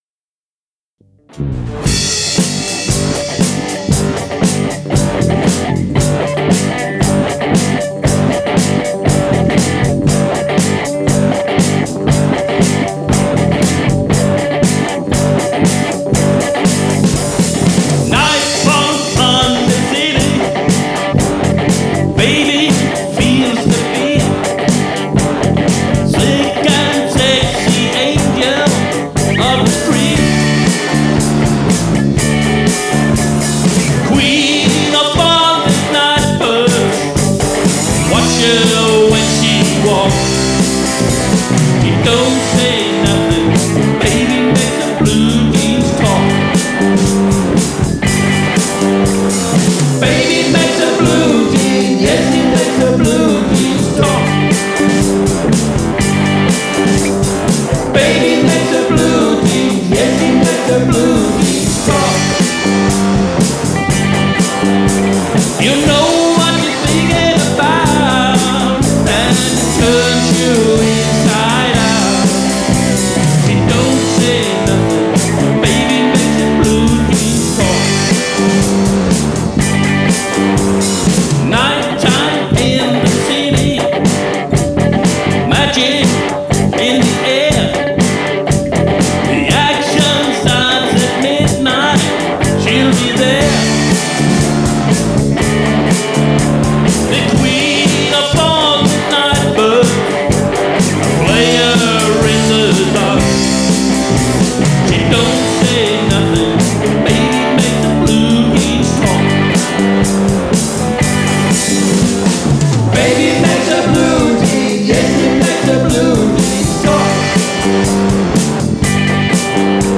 • Coverband
• Rockband